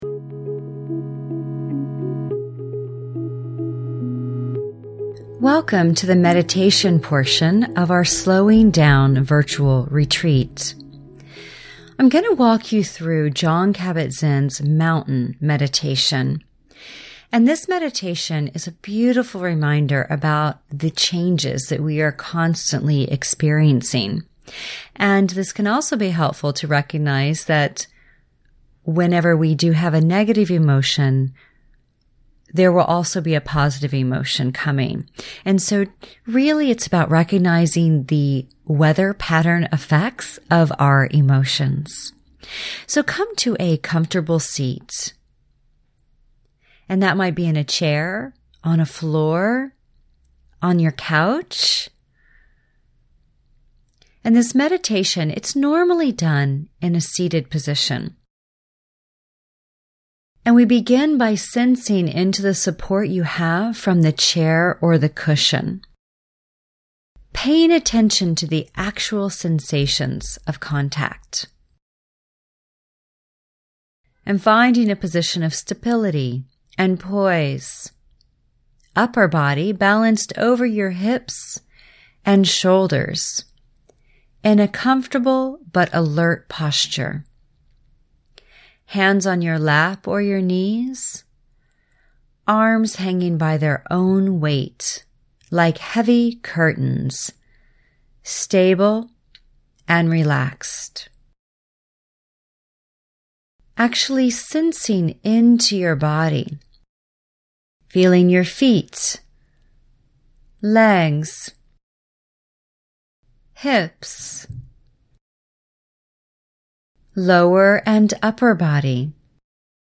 Mountain meditation (20:00)
VR3-seated-meditation.mp3